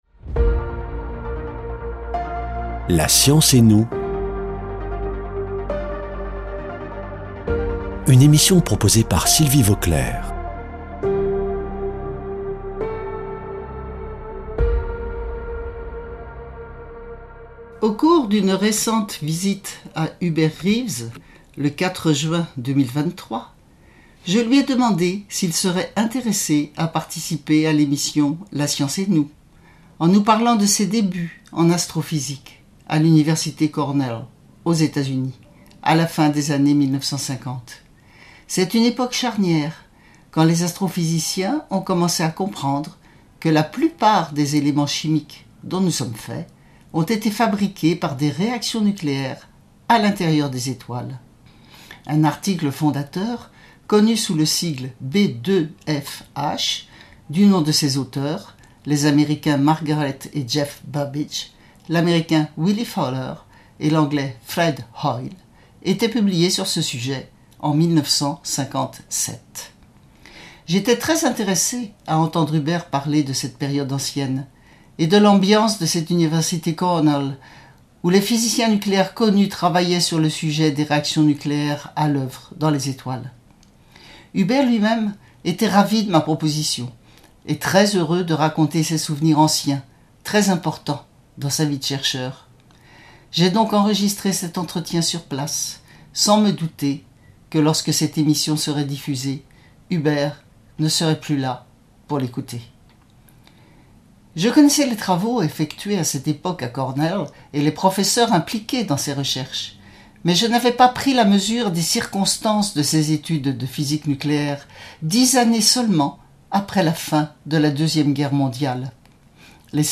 [ Rediffusion ] Hubert Reeves raconte ses débuts d’étudiant en astrophysique nucléaire à l’université Cornell (enregistrement 4 juin 2023)